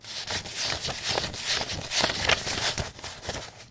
马蹄下坡小跑声
描述：一匹马下山坡的小跑声，24bit的29khz立体声录音。
标签： 立体声 剪辑 下坡 WAV 小跑
声道立体声